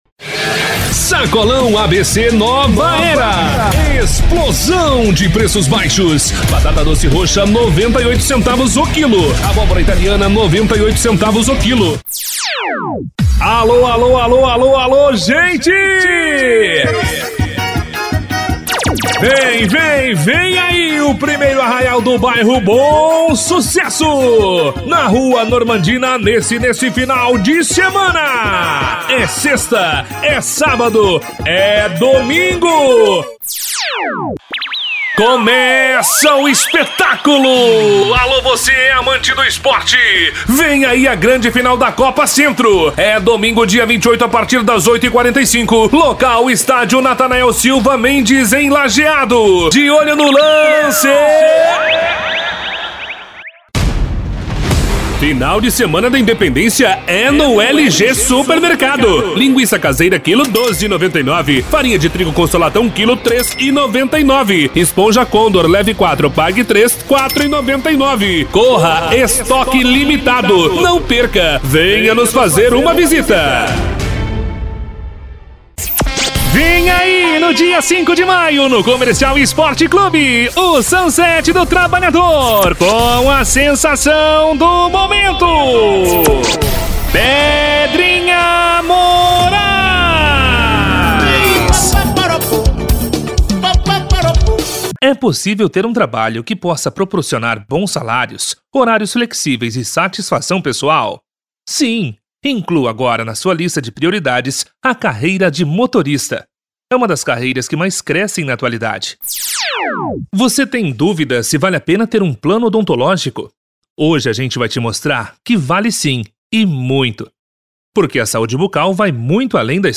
Animada
Caricata
Locutor ótimo para chamadas tipo narração de futebol.